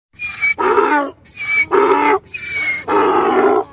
06-szamar.mp3